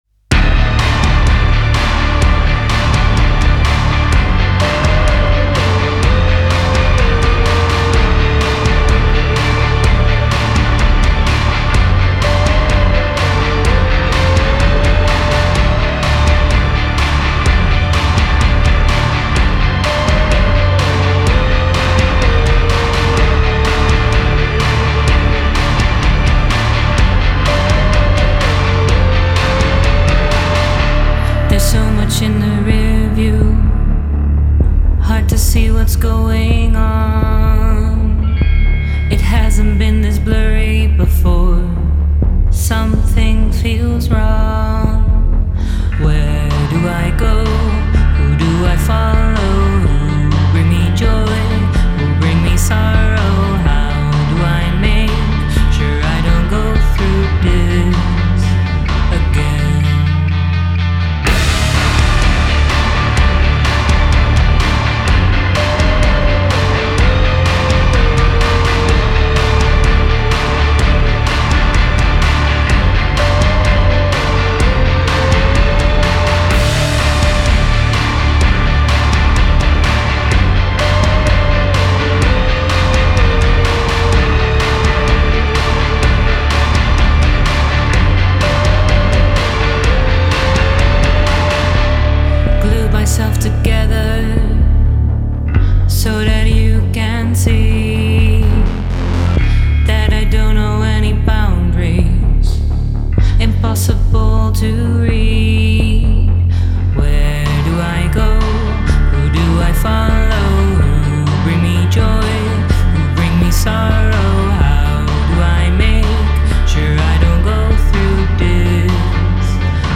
gothic rock Darkwave